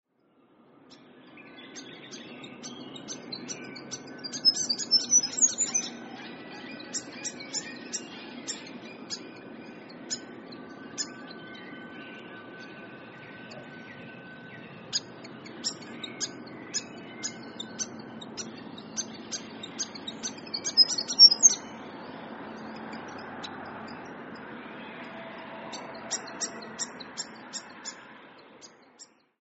Grey Fantail - Rhipidura fuliginosa
Voice: frequent sharp 'dek', sweet fiddle-like song, see-sawing and ascending to high note.
Call 1: 'dek' calls and song
Grey_Fantail.mp3